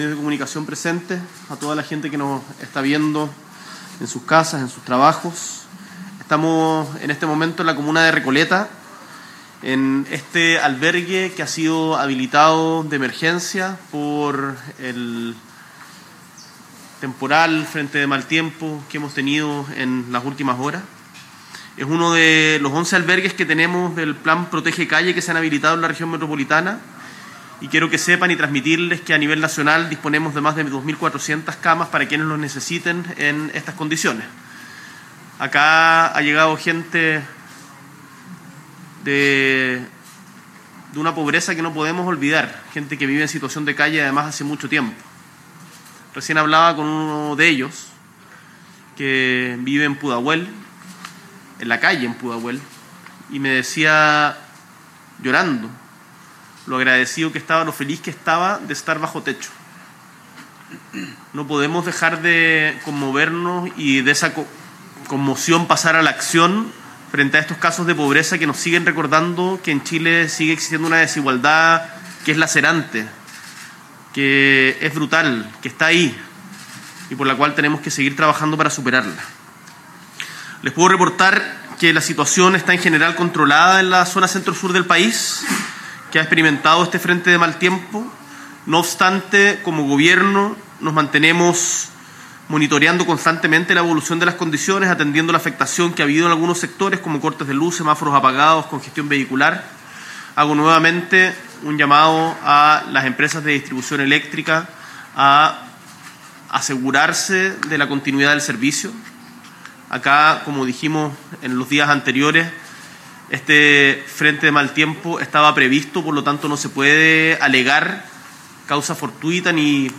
S.E. el Presidente de la República, Gabriel Boric Font, visita albergue de emergencia para personas en situación de calle en Recoleta.